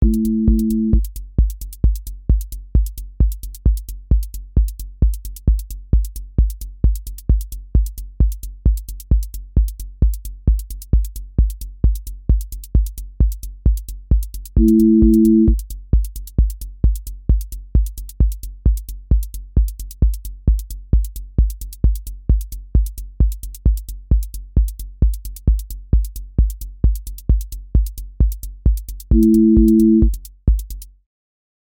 QA Listening Test techno Template: techno_hypnosis
• voice_kick_808
• voice_hat_rimshot
• voice_sub_pulse
• tone_brittle_edge
• motion_drift_slow
Techno pressure with driven motion